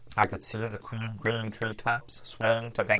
nfo_chp24_utt034.flac Strong distortion